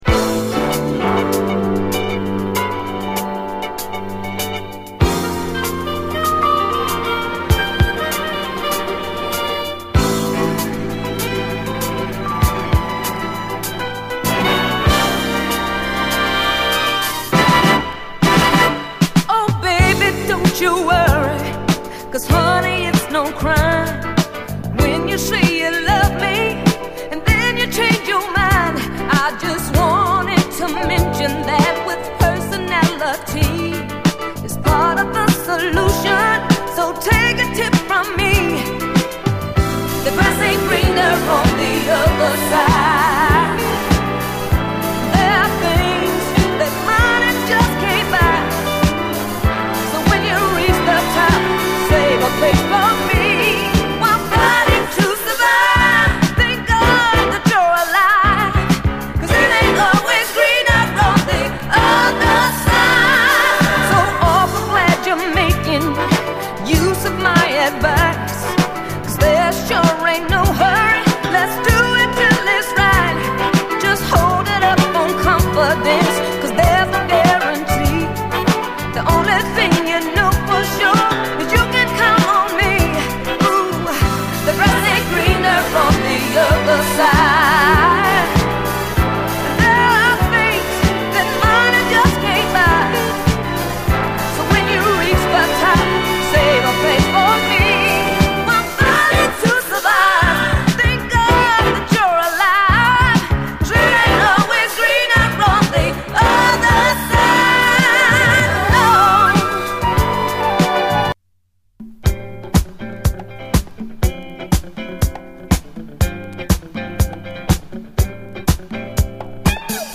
盤見た目少しスレありますが実際は概ね綺麗に聴けます。
MONO